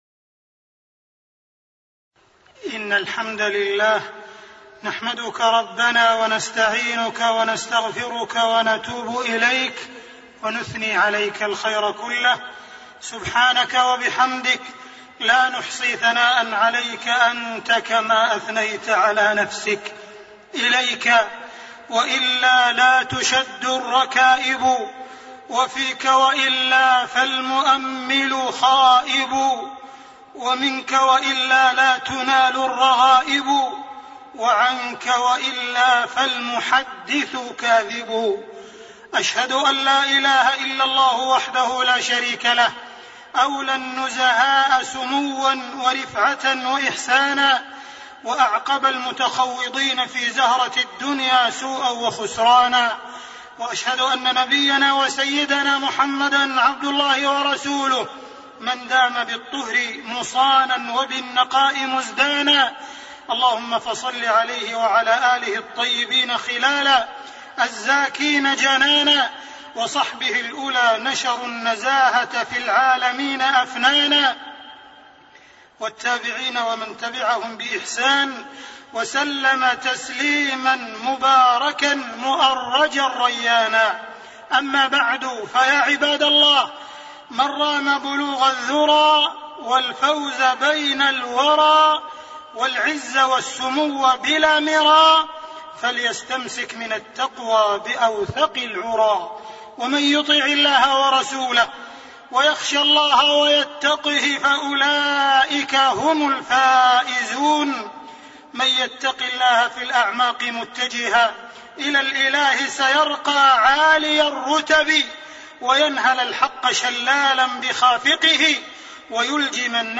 تاريخ النشر ٢٣ جمادى الآخرة ١٤٣٤ هـ المكان: المسجد الحرام الشيخ: معالي الشيخ أ.د. عبدالرحمن بن عبدالعزيز السديس معالي الشيخ أ.د. عبدالرحمن بن عبدالعزيز السديس النزاهة والرشوة